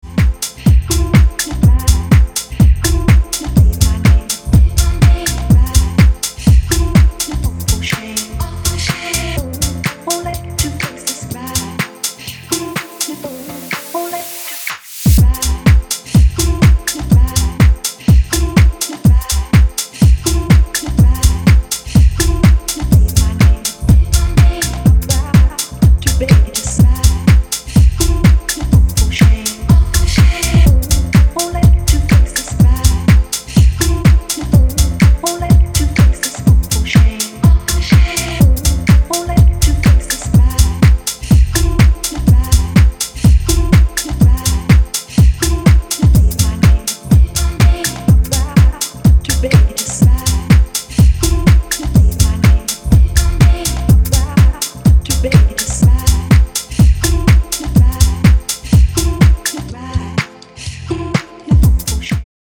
各曲、煽りに煽るフィルター使いとマッシヴなボトムの威力が光ります。